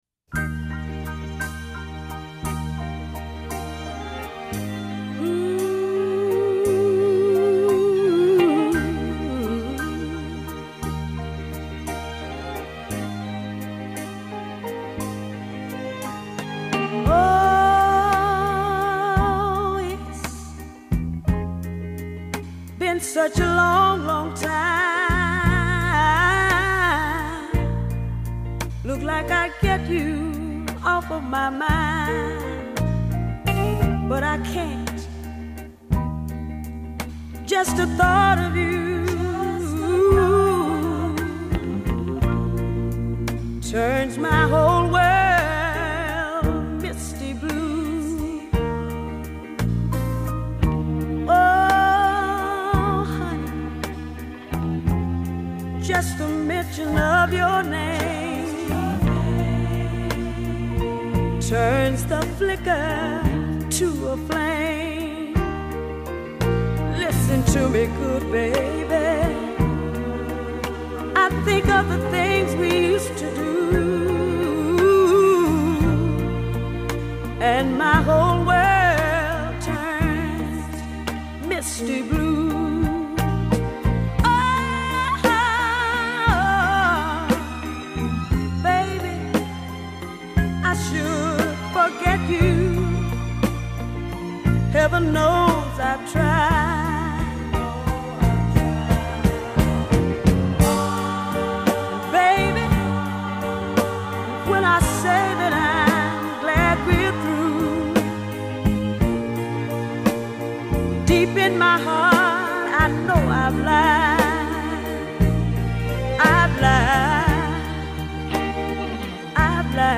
soulful torch rendition